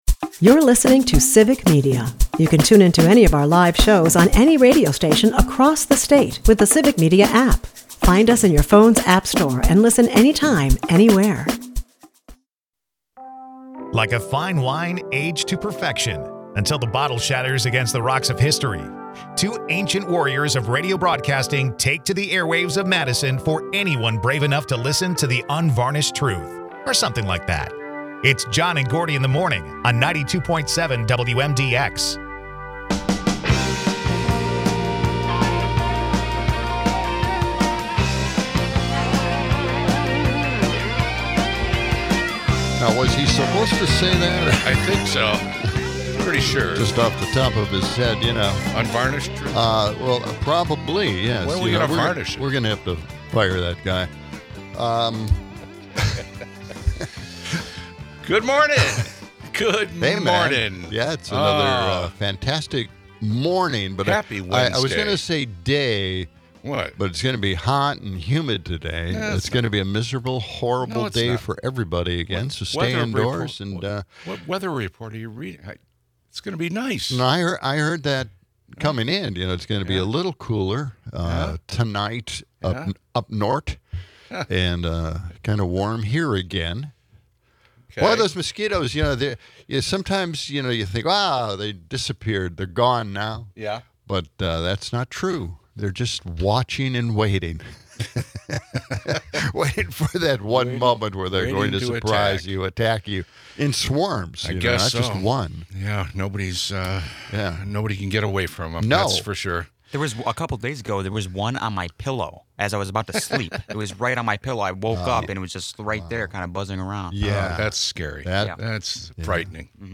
A Canadian doctor dispels myths about healthcare, taking aim at the U.S. system. The duo also critique the Trump tariffs, predicting economic repercussions.